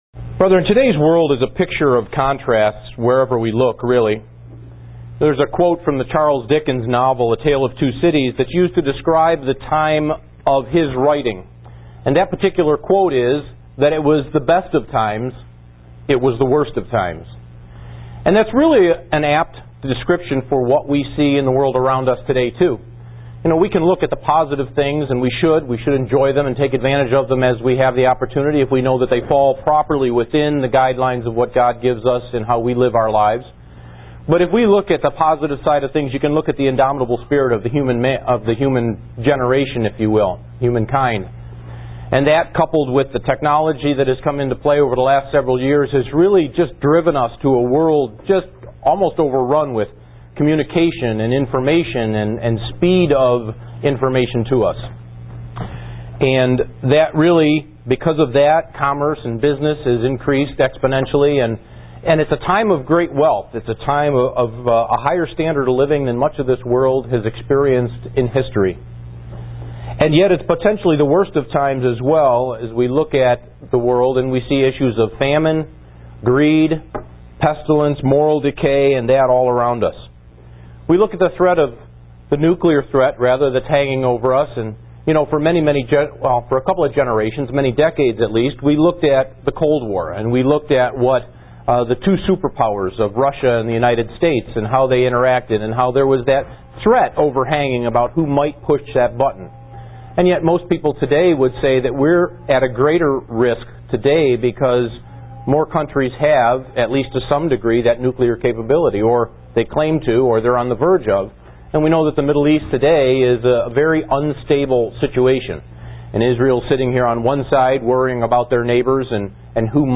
Given in Buffalo, NY
Kingdom of God Seminar 3: Part 1 UCG Sermon Studying the bible?